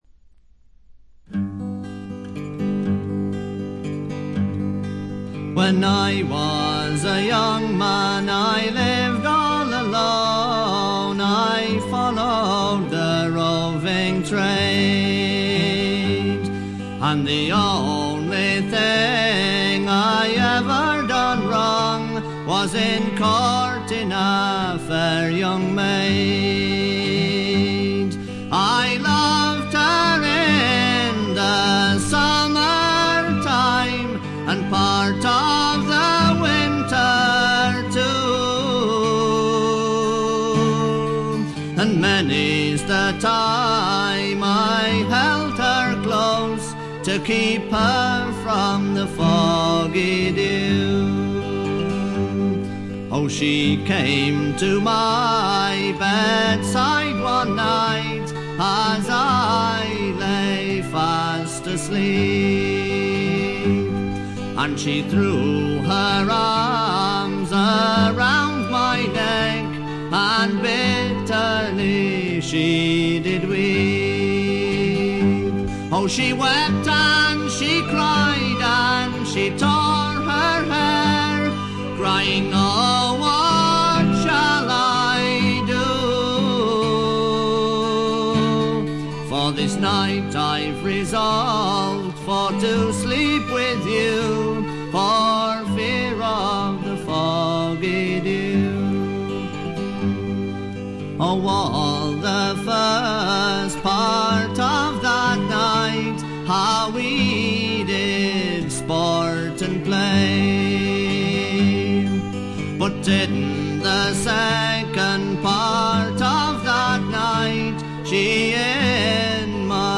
静音部の微細なチリプチが少し、散発的なプツ音が3−4回出る程度でほとんどノイズ感無し。
ちょっとしゃがれた苦味のあるヴォーカルもとてもよいです。
試聴曲は現品からの取り込み音源です。